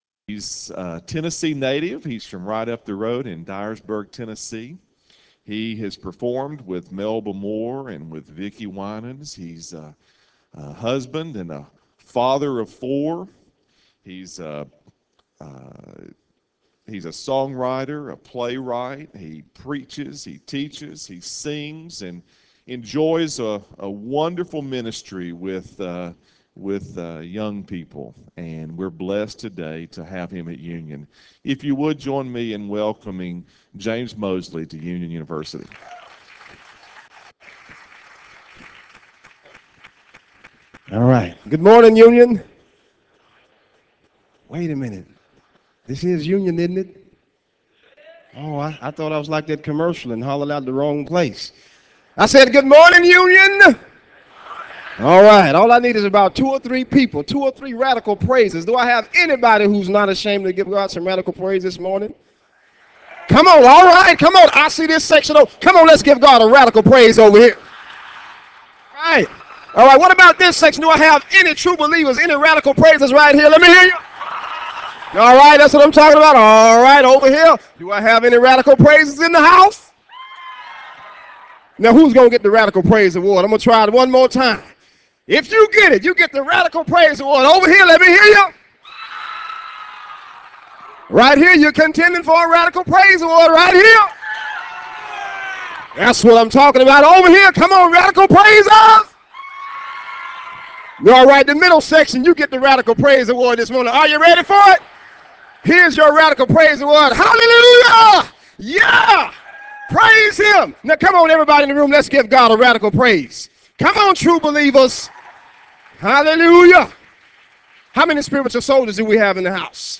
Chapel Service